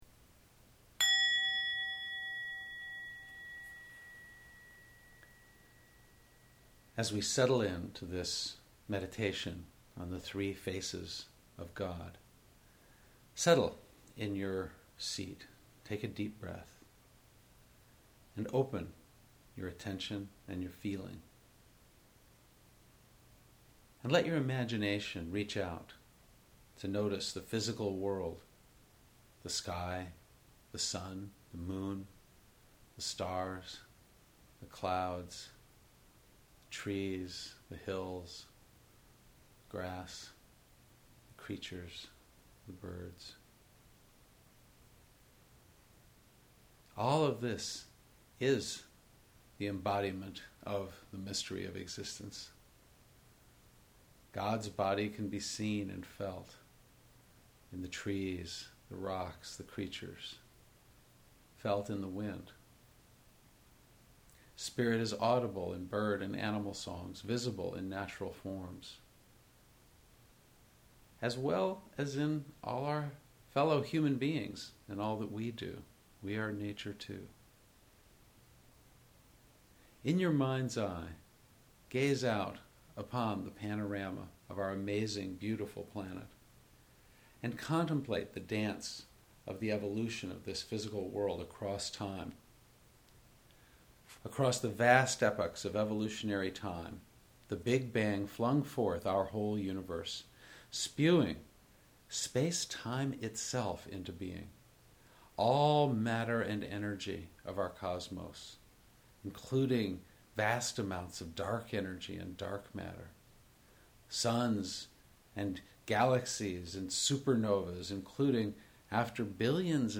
02_experiencing_the_3_faces_of_god-guided_meditation.mp3